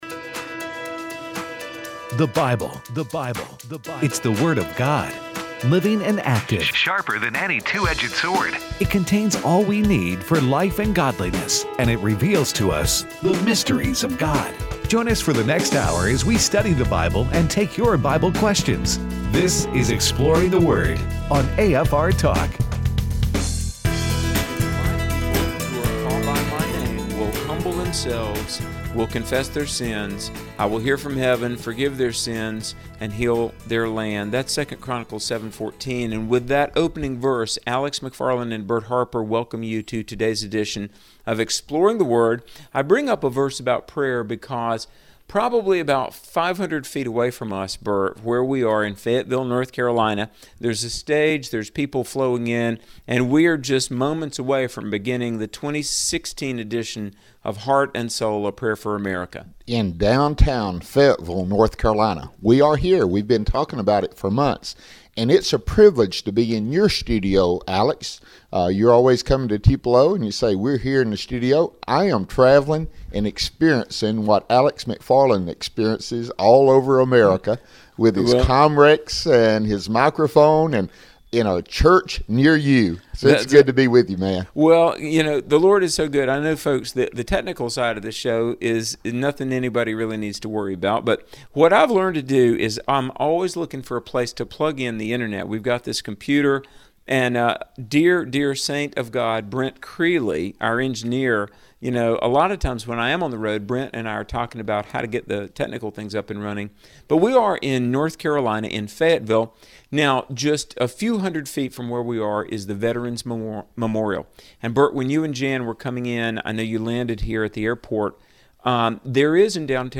Also, it's Fireaway Friday where listeners call in with their Bible questions throughout the show.